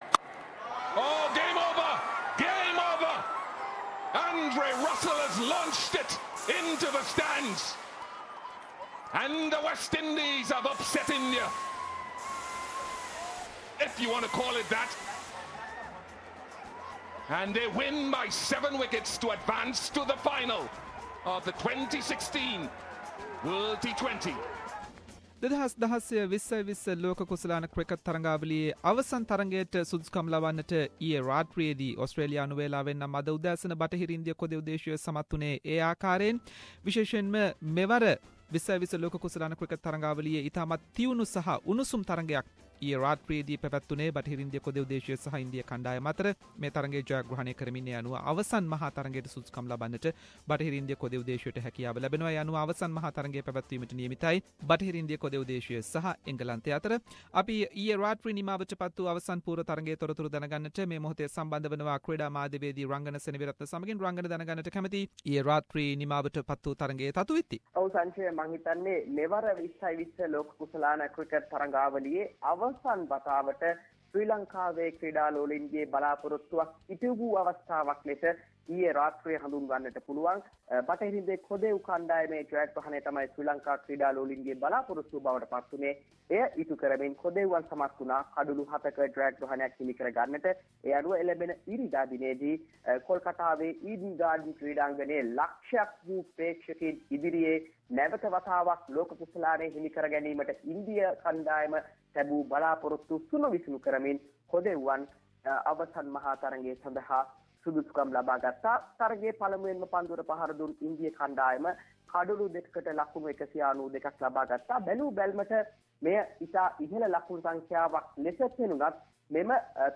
Latest from T20 World cup and related stories. Sports journalist